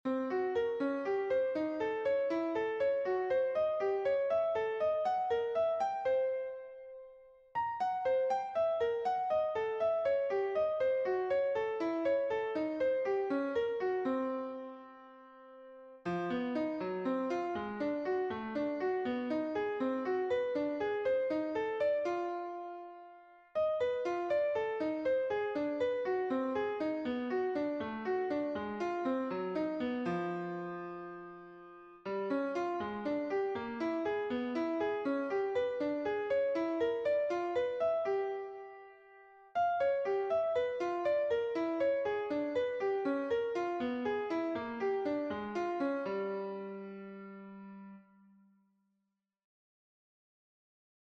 Improvisation Piano Jazz
Licks avec des quartes
Sur Gamme Diminuée
lick_quartes_dim.mp3